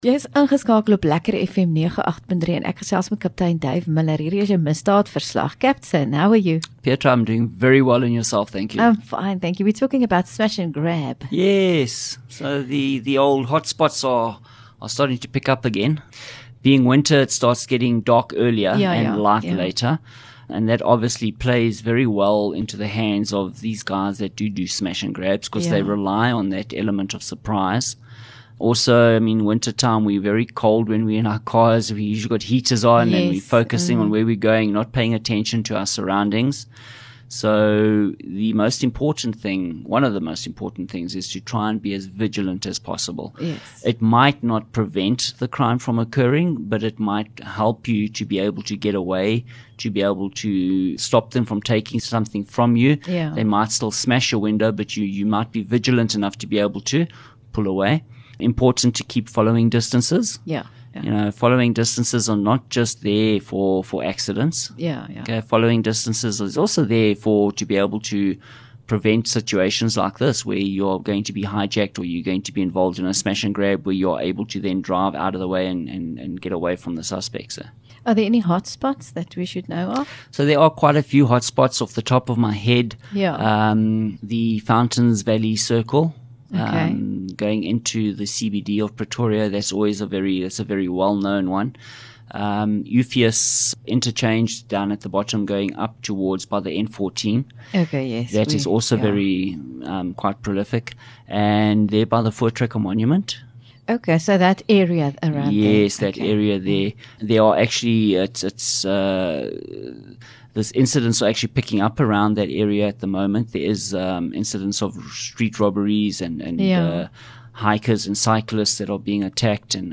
LEKKER FM | Onderhoude 20 Jun Misdaadverslag